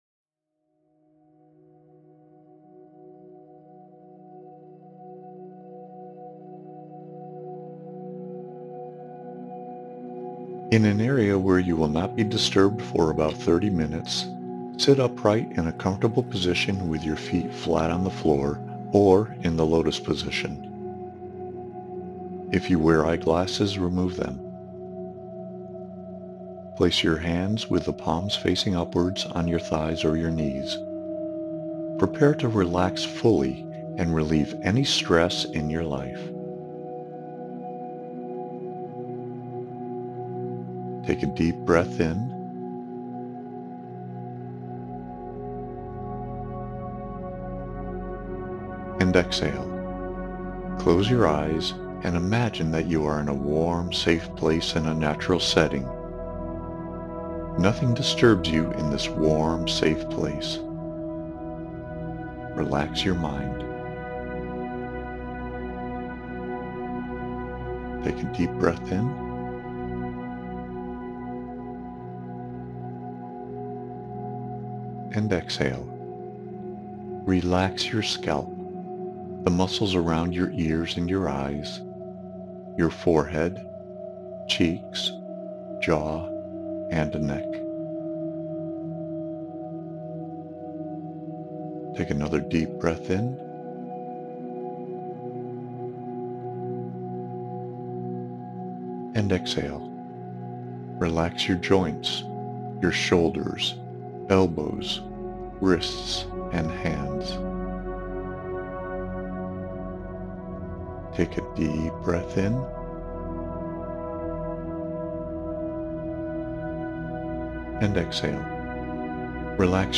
BasicGuidedMeditation.mp3